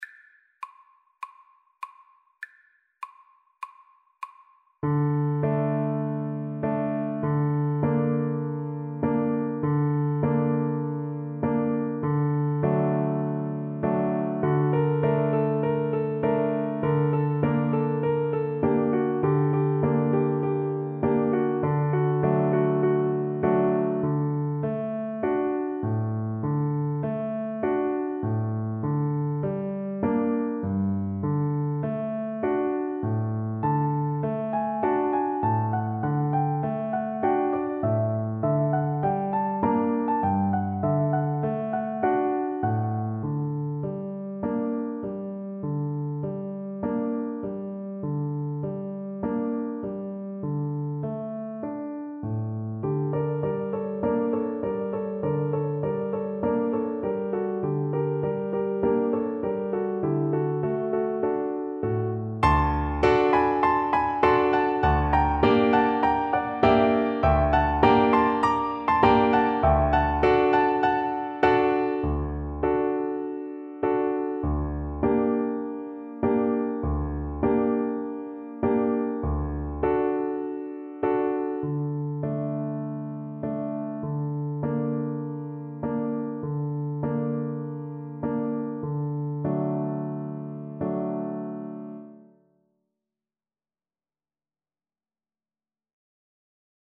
Cello
Traditional Music of unknown author.
4/4 (View more 4/4 Music)
D major (Sounding Pitch) (View more D major Music for Cello )
Moderato